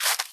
Sfx_creature_penguin_foot_slow_walk_05.ogg